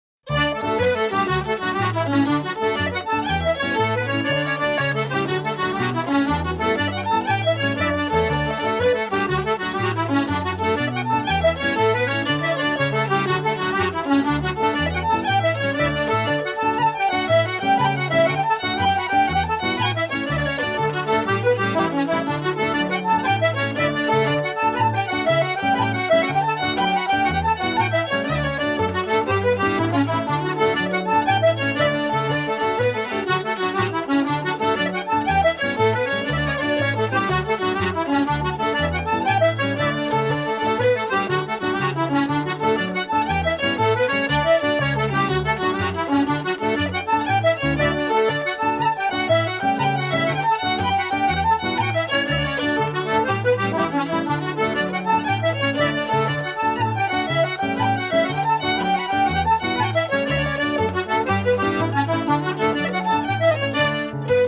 Accordion
With guest musicians
Piano
Harp
Fiddle
Irish traditional music